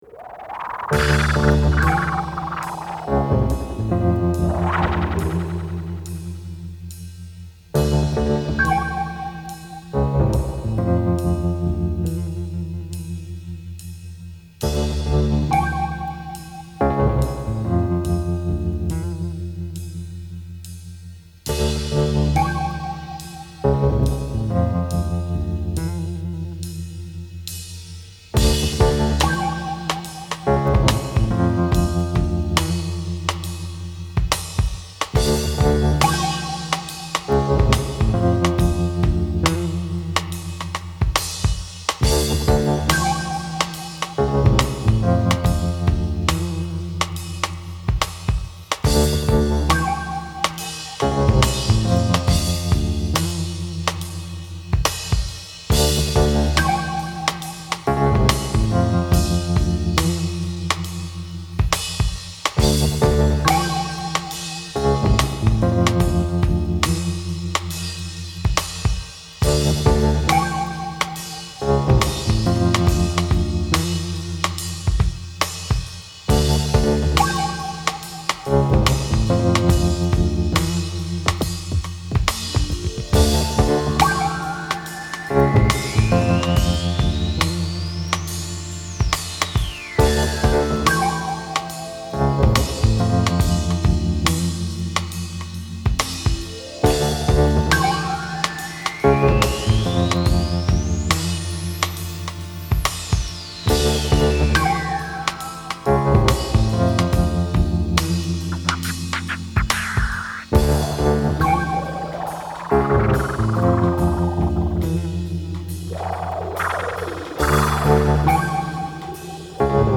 Uneasy slow groove with underscores.